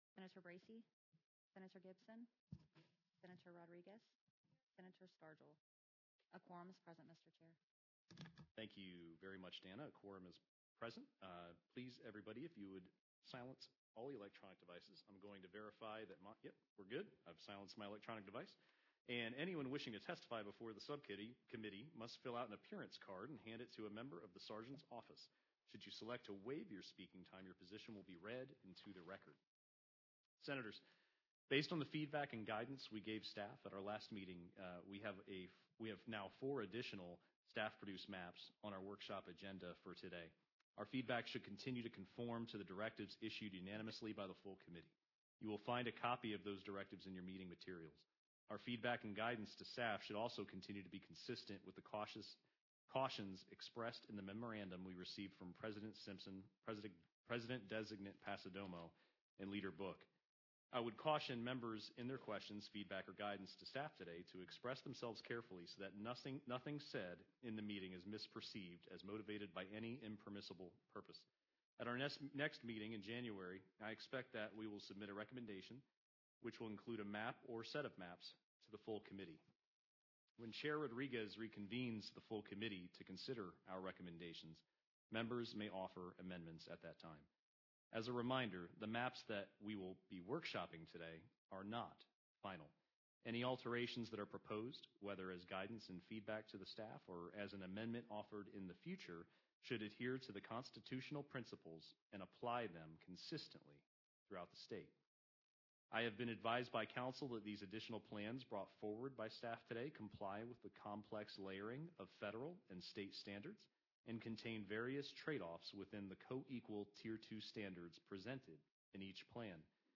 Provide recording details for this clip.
Select Subcommittee on Congressional Reapportionment Location: 412 Knott Building Meeting Records Meeting Notice [PDF] Meeting Packet [PDF] Attendance [PDF] Expanded Agenda [PDF] Audio [MP3] Video [MP4]